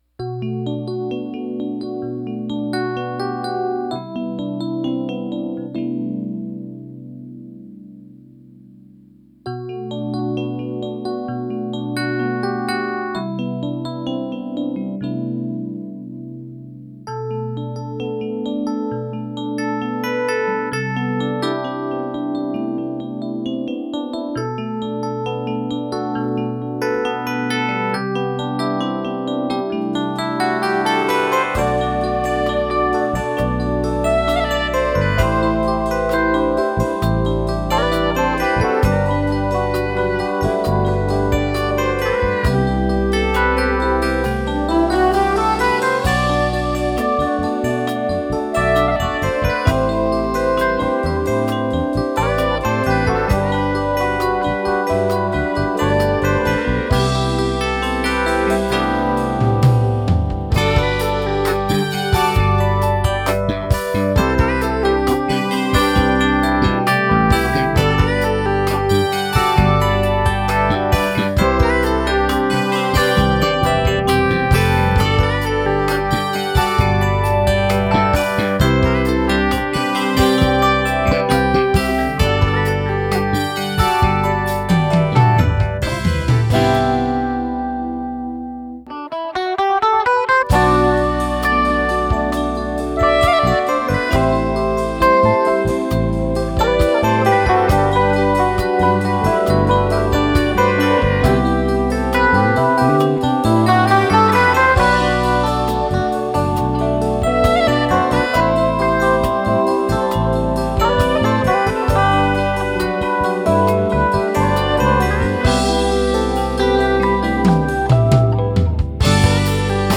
keyboards, synthesizer solos.
electric guitar.
electric bass.
drums.
saxophone.
Studio in New York, NY